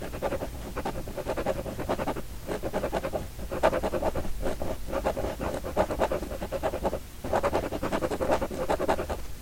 绘画
描述：在一张纸上涂鸦